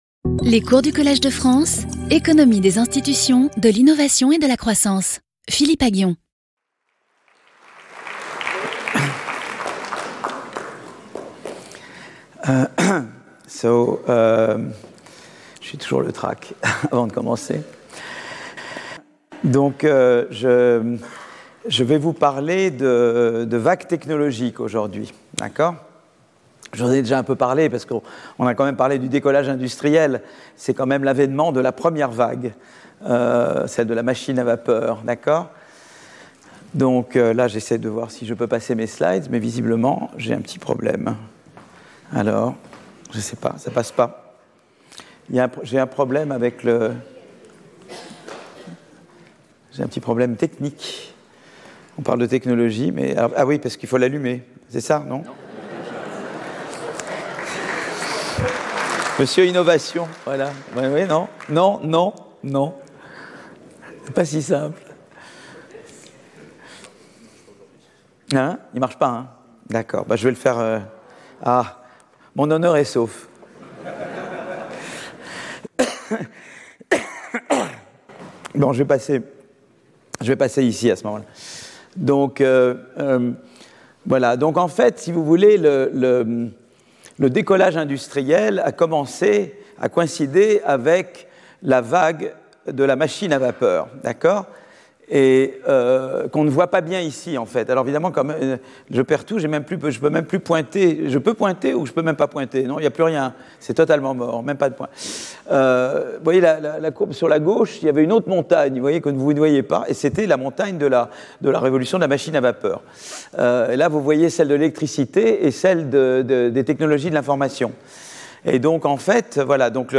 Philippe Aghion Professeur du Collège de France
Cours